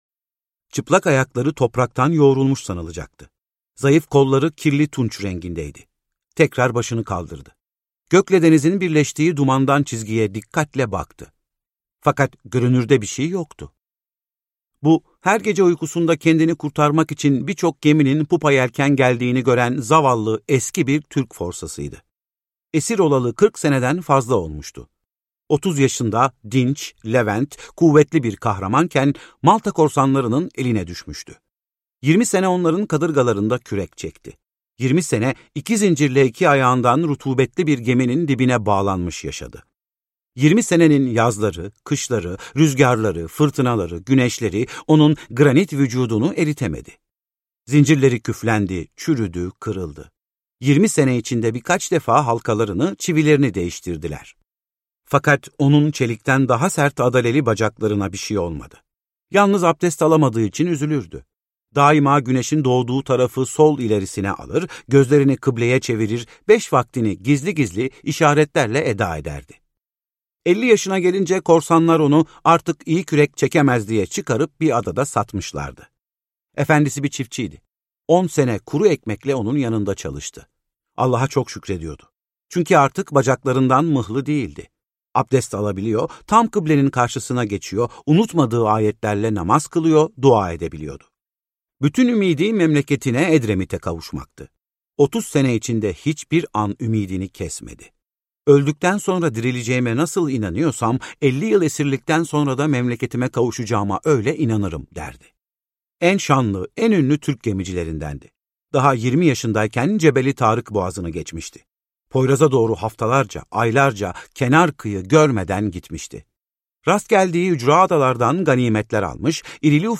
Forsa - Seslenen Kitap